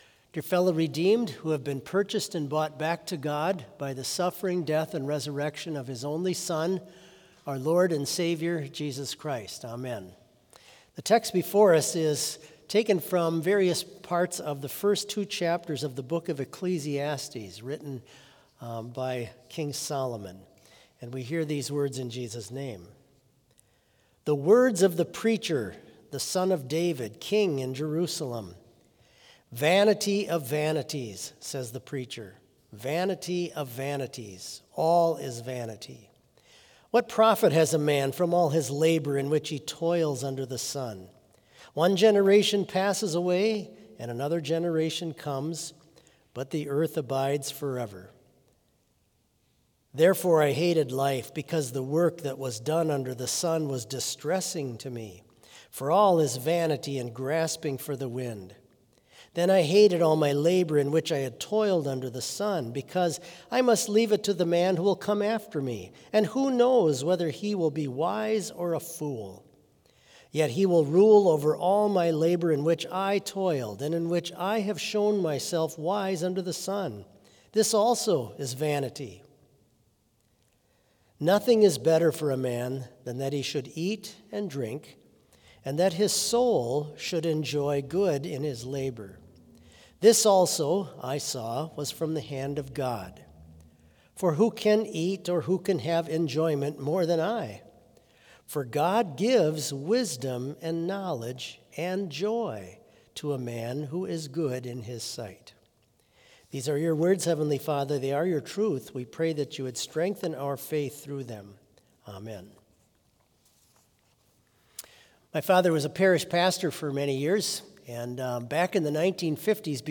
Complete service audio for Vespers - Wednesday, November 12, 2025